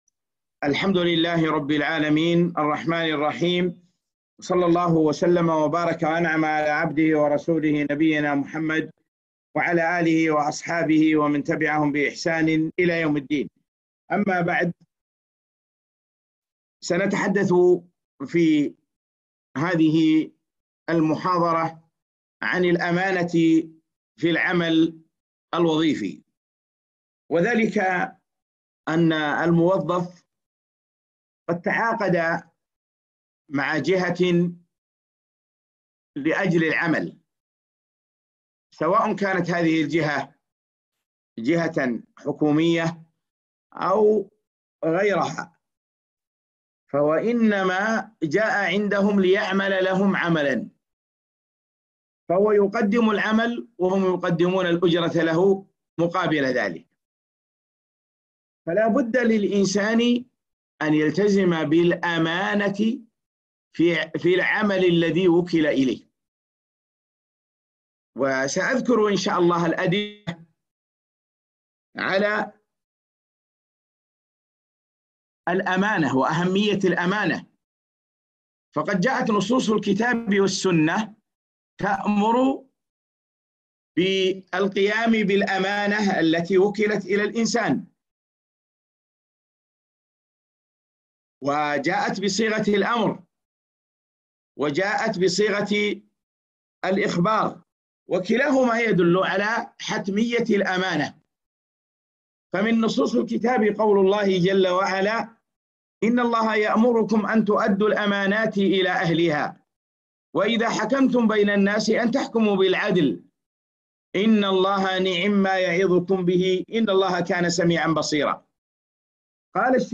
محاضرة - وجوب الأمانة في العمل الوظيفي